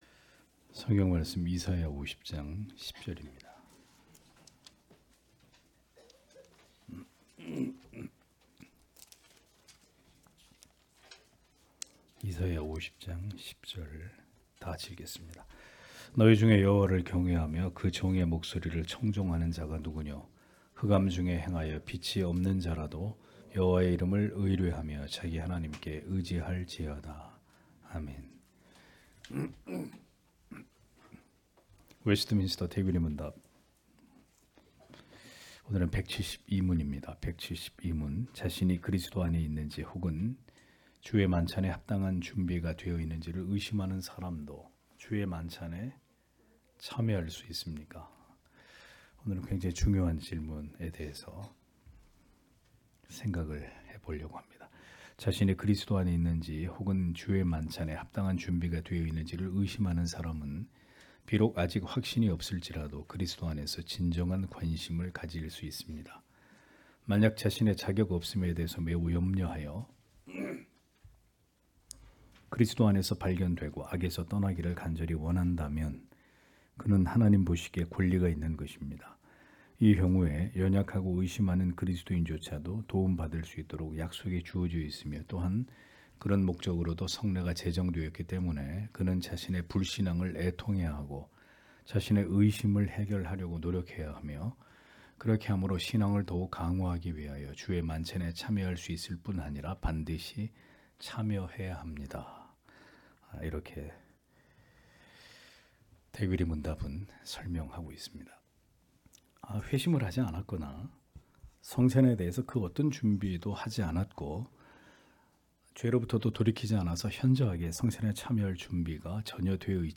주일오후예배 - [웨스트민스터 대요리문답 해설 172] 172문) 자신이 그리스도 안에 있는지 혹은 성찬에 합당한 준비가 되어있는지 의심하는 자도 성찬에 참여할 수 있을까? (이사야 50장 10절)